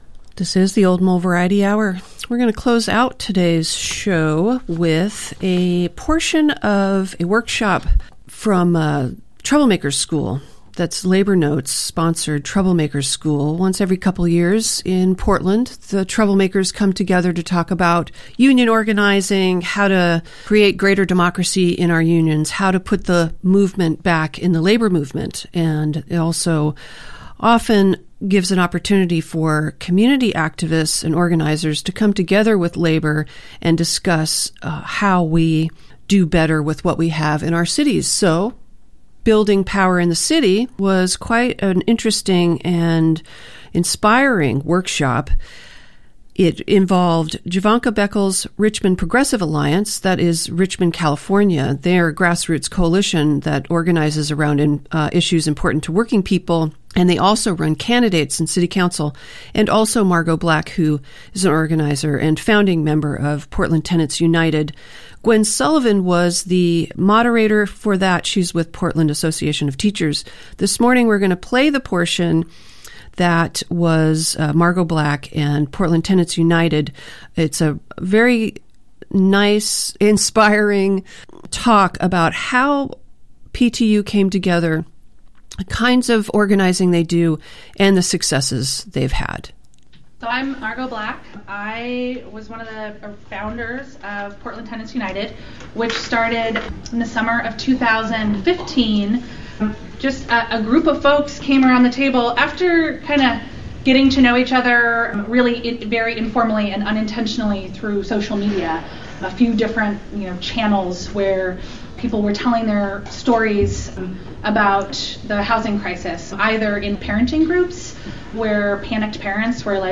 Panel discusion